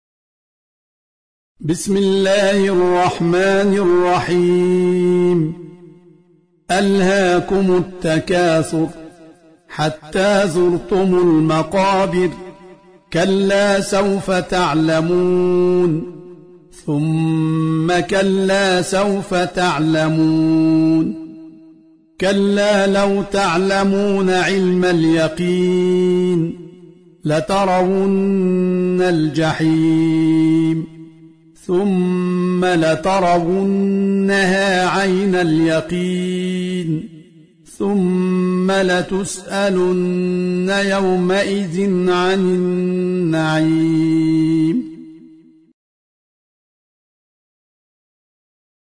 سورة التكاثر - المصحف المرتل
جودة فائقة